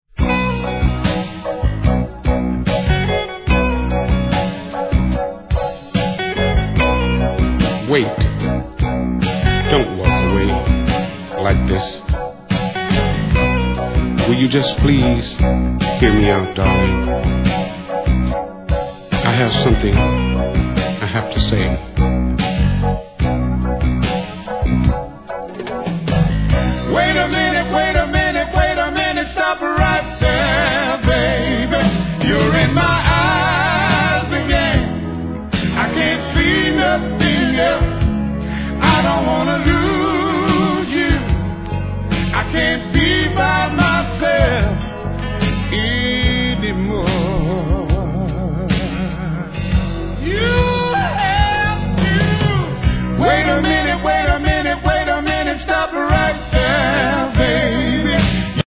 SOUL/FUNK
1990年、素晴らしいコーラスで聞かせるSLOWナンバーからRAPまで披露してます!